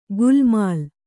♪ gulmāl